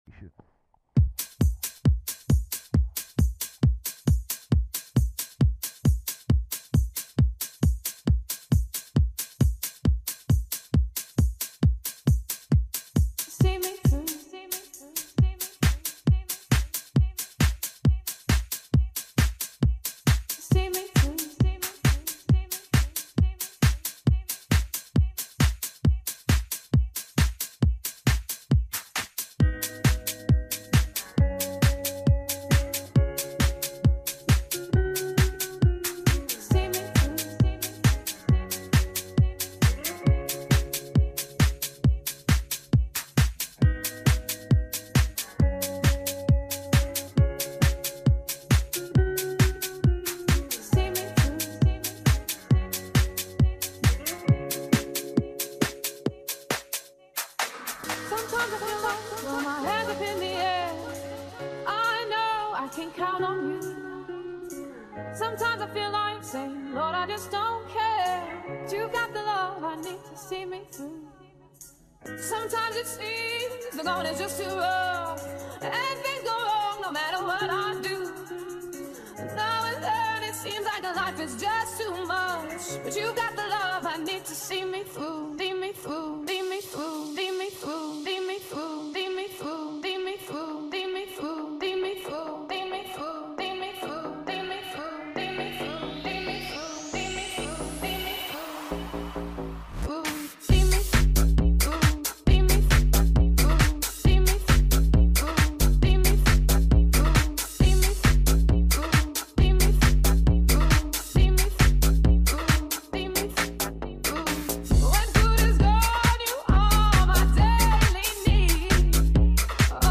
Bassline Bounce House